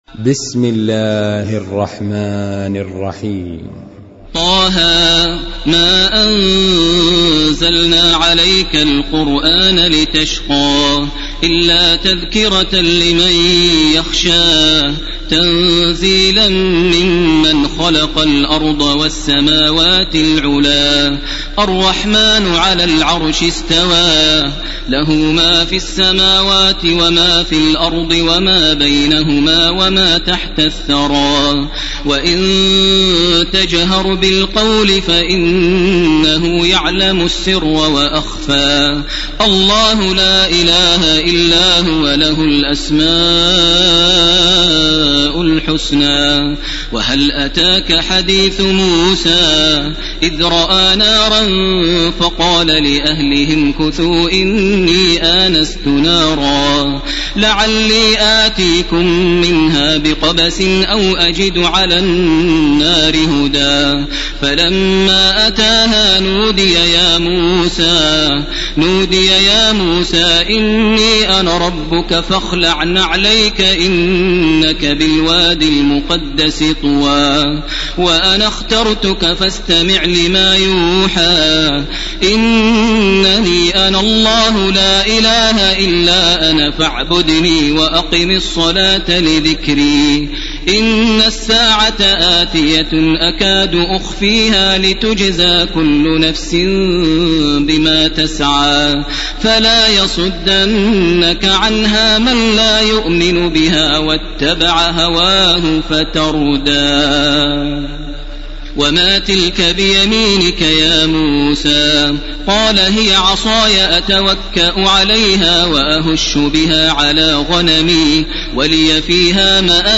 ليلة 16 رمضان لعام 1431 هـ سورة طه كاملة. > تراويح ١٤٣١ > التراويح - تلاوات ماهر المعيقلي